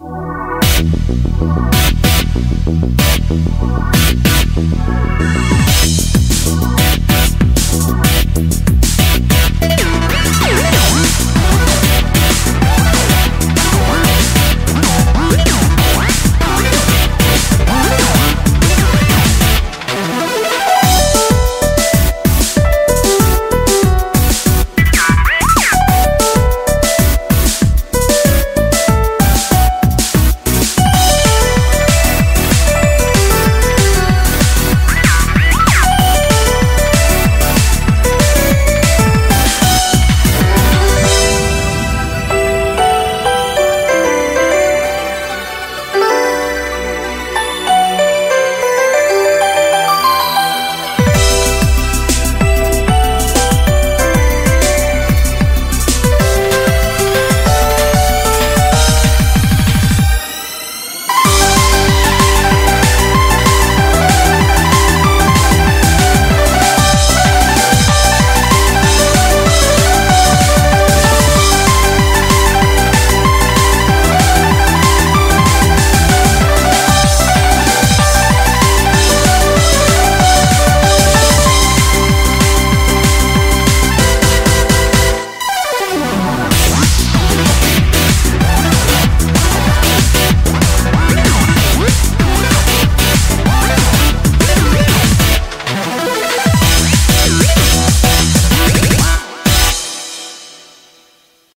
BPM190
Comments[TEK-TRANCE]